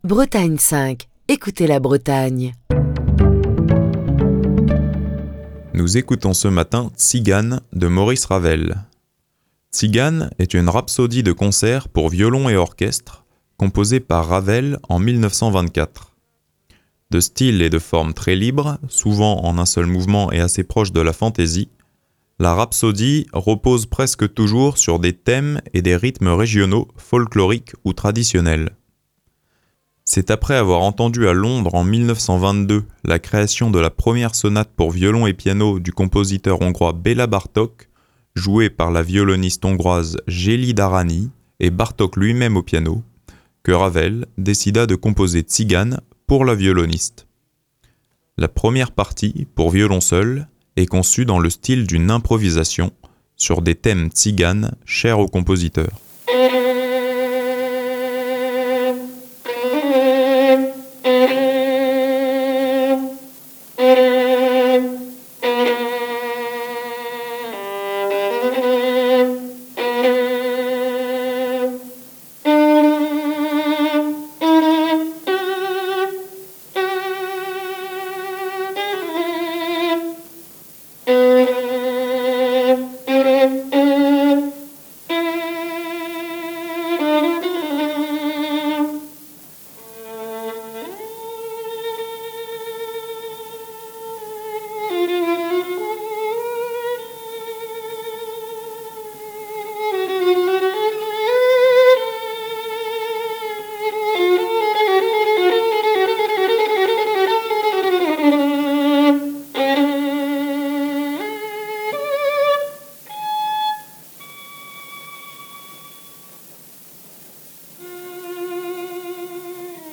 Tzigane est une rhapsodie de concert pour violon et orchestre, composée par Maurice Ravel en 1924. De style et de forme très libres, souvent en un seul mouvement et assez proche de la fantaisie, la Rhapsodie repose presque toujours sur des thèmes et des rythmes régionaux, folkloriques ou traditionnels.
La première partie, pour violon seul, est conçue dans le style d'une improvisation sur des thèmes tziganes chers au compositeur. Tzigane de Maurice Ravel, l’une des pièces pour violon les plus virtuoses, que je vous propose d’entendre est jouée par Ginette Neveu, violoniste française née en 1919 et décédée tragiquement à seulement 30 ans dans l’accident d’avion où se trouvait également le boxeur Marcel Cerdan.